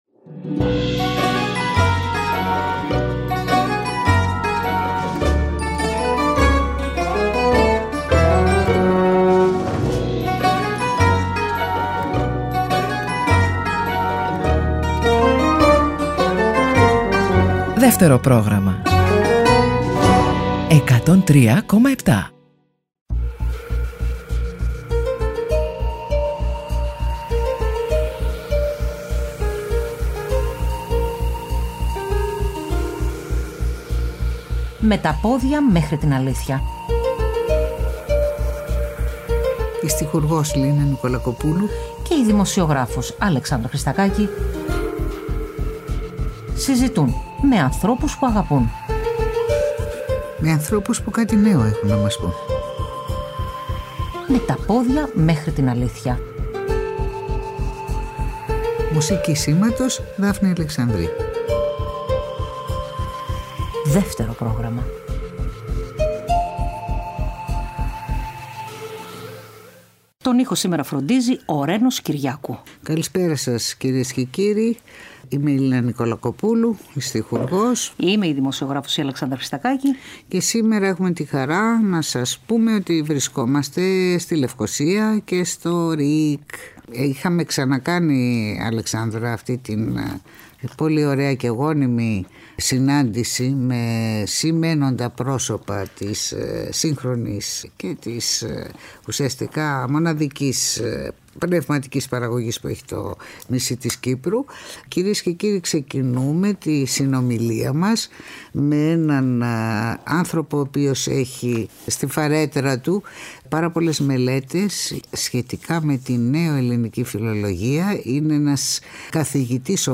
Συζήτησαν στο στούντιο του ΡΙΚ